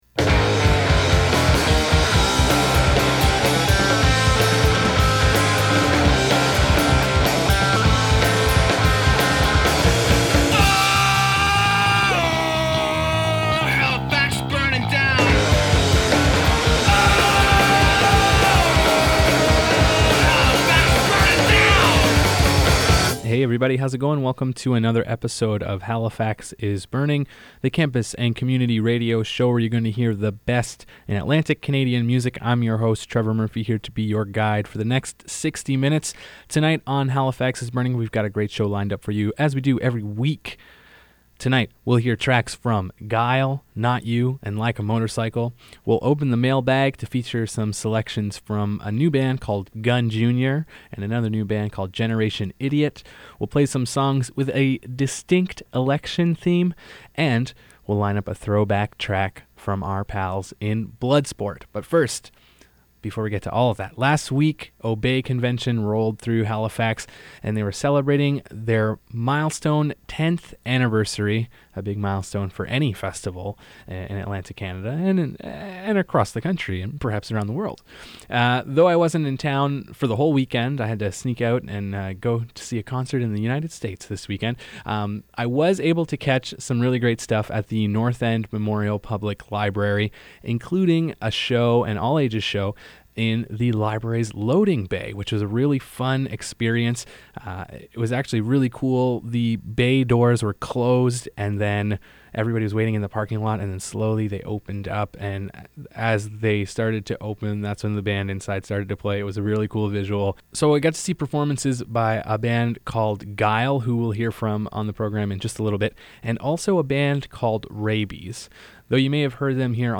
The best independent East Coast music.